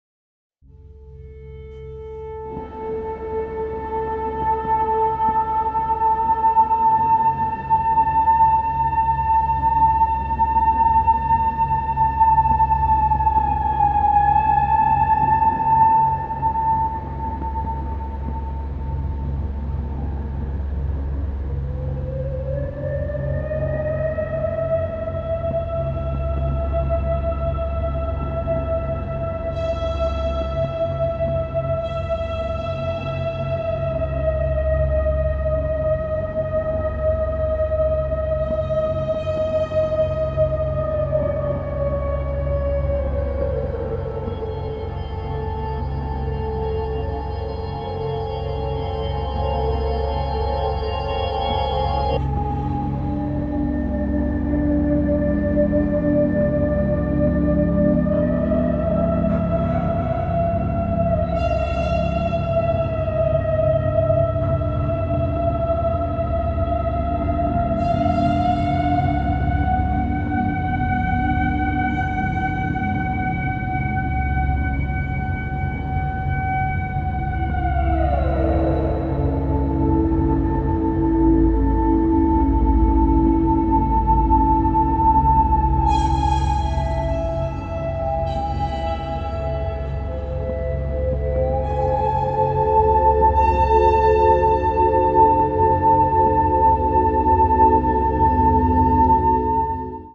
霊的な音世界を是非！